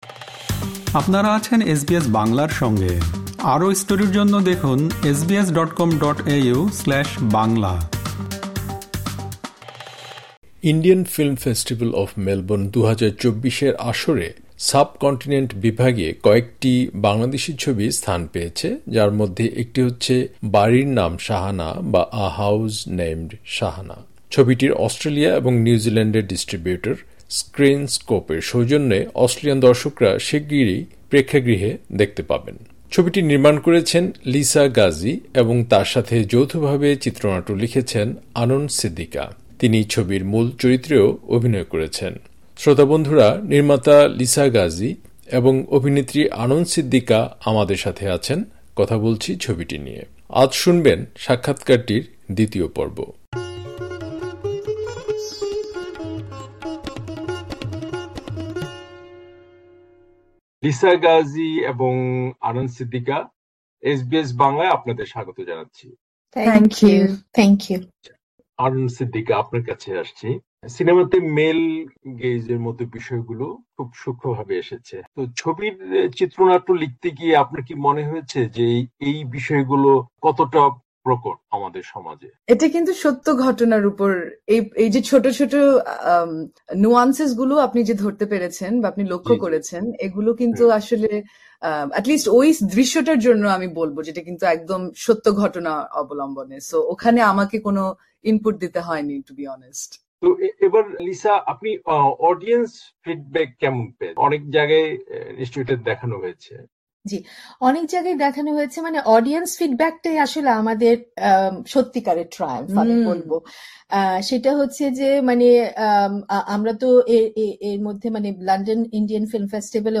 'বাড়ির নাম শাহানা' - নারীদের সাথে পুরুষ দর্শকরাও ছবির গল্পে তাদের সম্পর্ক খুঁজে পেয়েছে; সাক্ষাৎকারের ২য় পর্ব